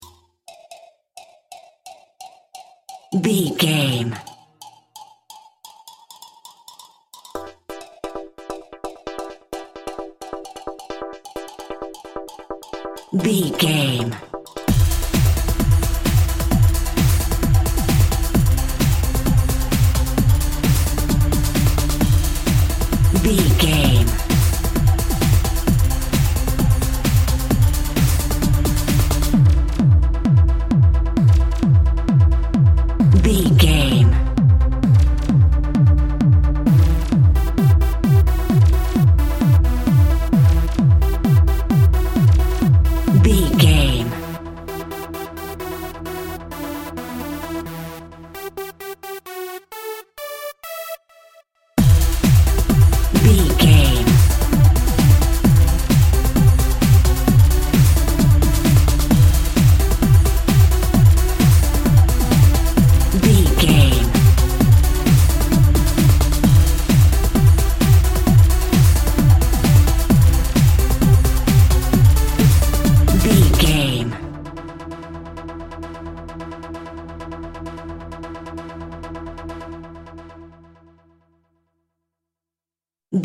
Aeolian/Minor
electronic
dance
techno
trance
synth lead
synth bass